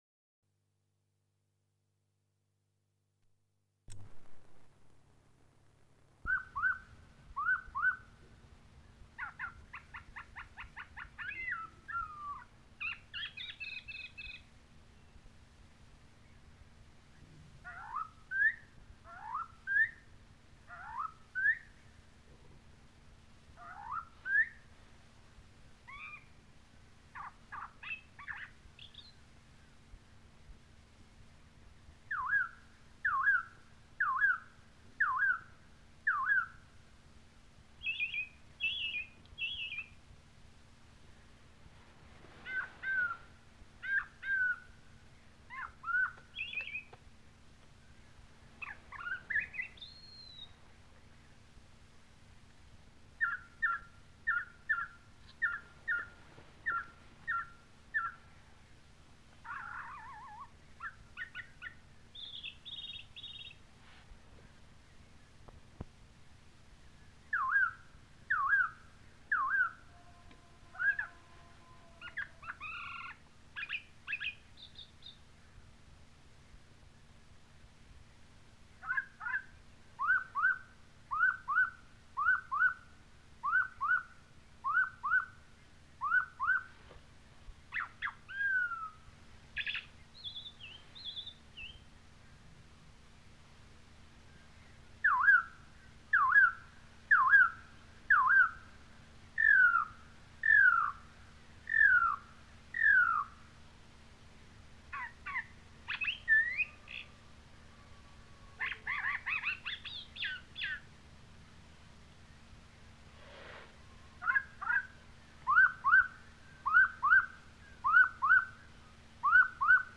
Chants d'oiseaux
Aire culturelle : Savès
Lieu : Gers
Genre : paysage sonore